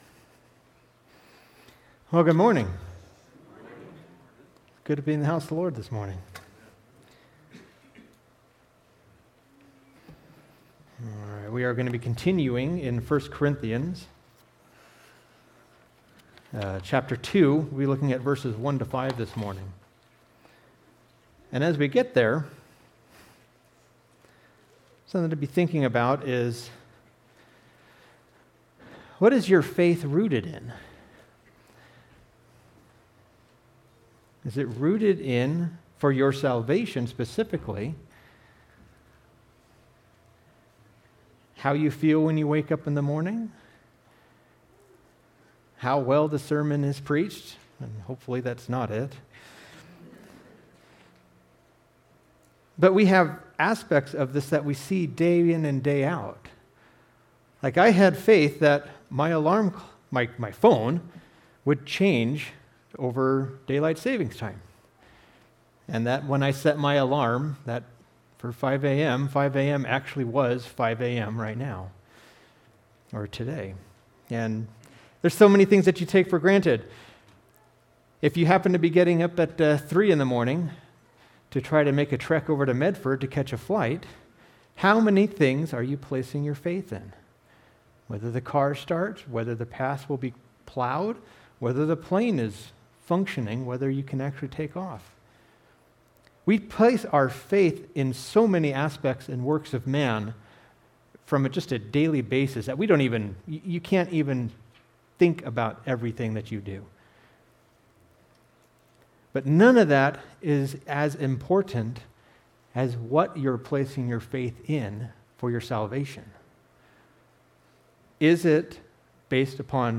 Our Sermons – Immanuel Baptist Church